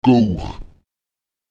uwó [«U] wird wie das oa im englischen soap artikuliert.
Lautsprecher guwóq [g«Ux] der Hut